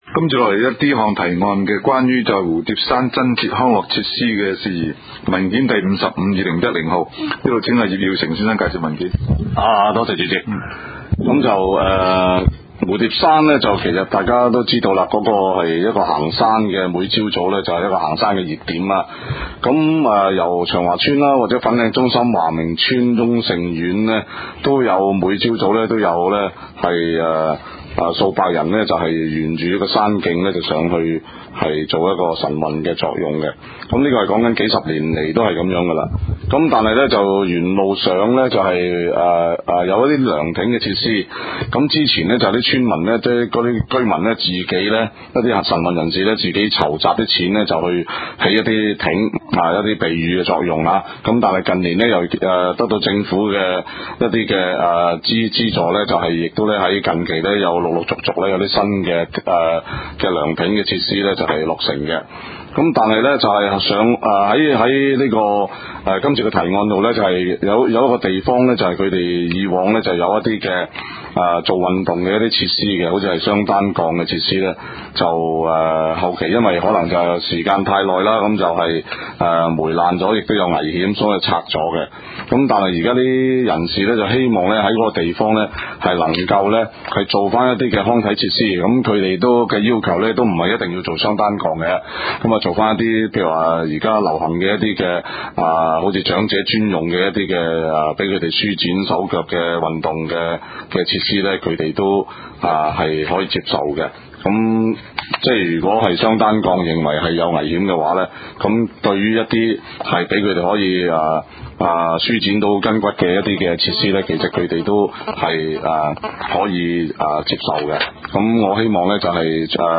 地點 北區區議會會議室